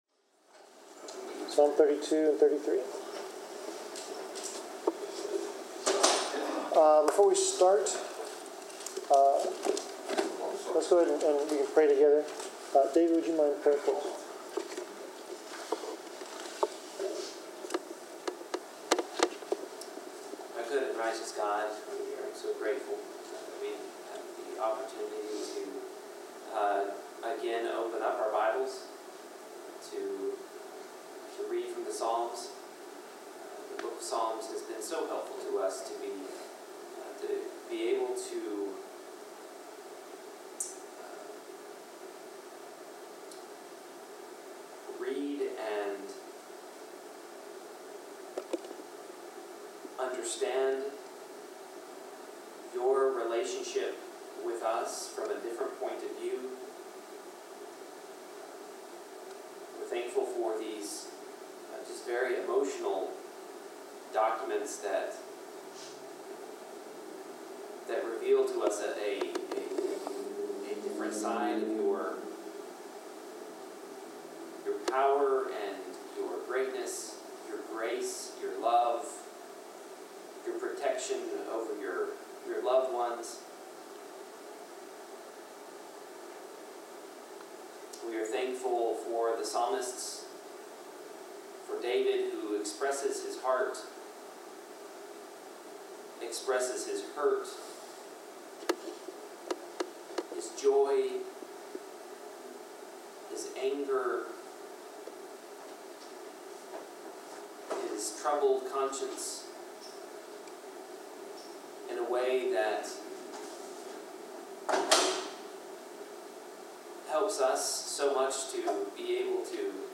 Bible class: Psalm 32
Service Type: Bible Class